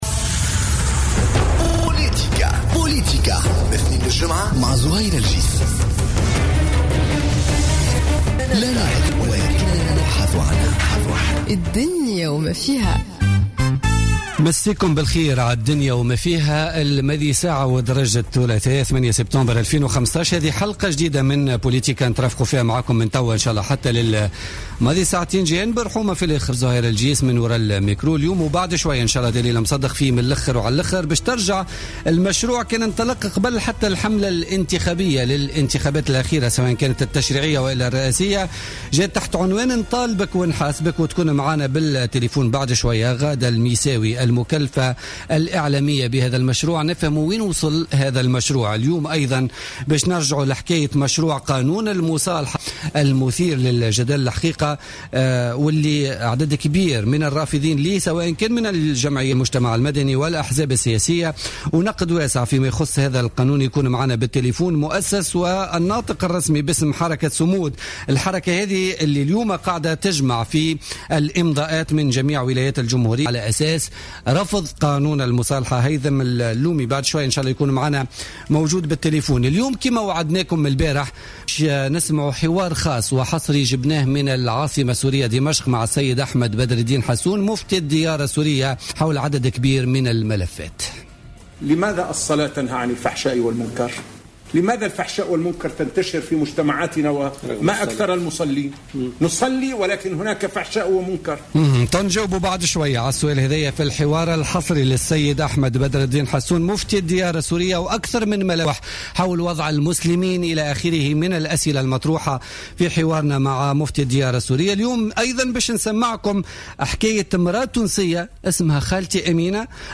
La mobilisation contre la réconciliation / Interview exclusive avec le grand mufti de la Syrie